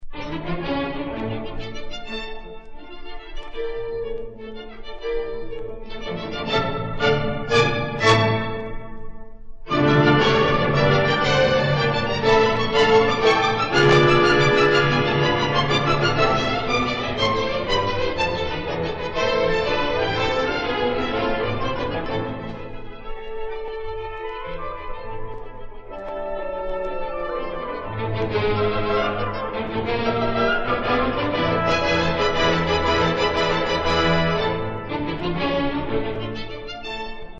Symphony in D Major (III.Scherzo, Czech Philharomnic Orchestra, cond. Karl Ancerl, Czech Lon Play Record 1950)